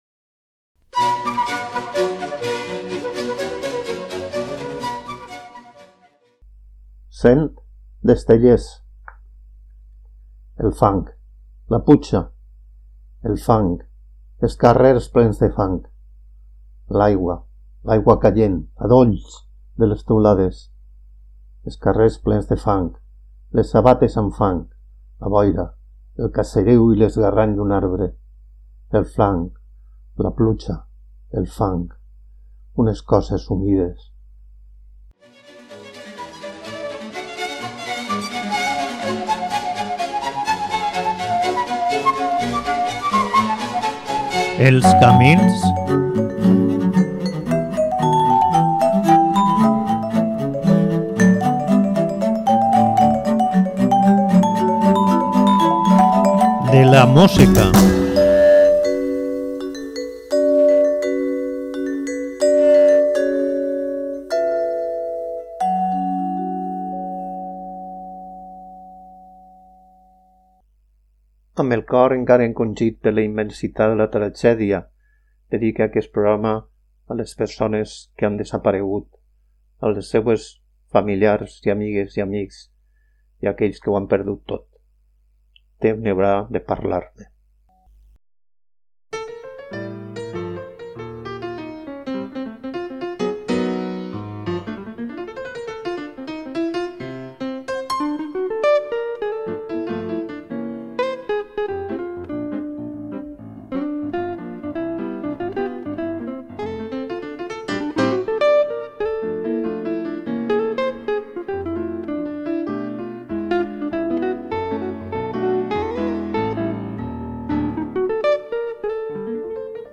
guitarrista